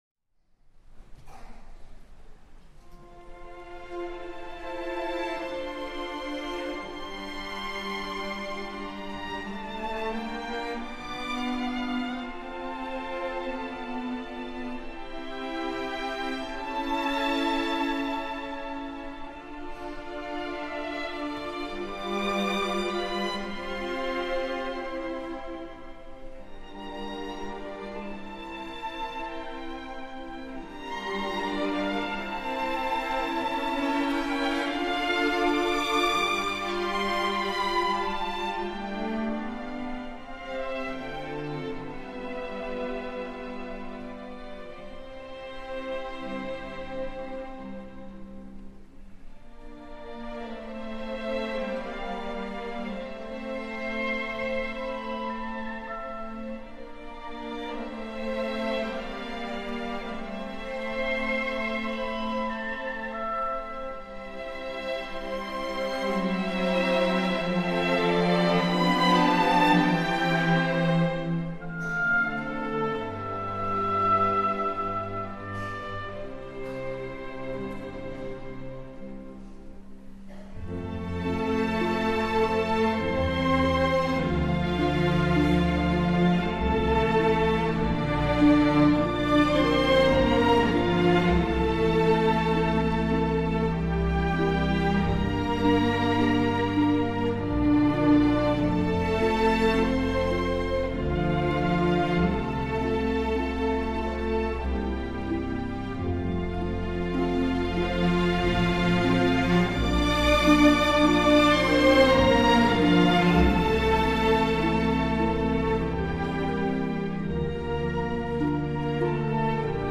管弦楽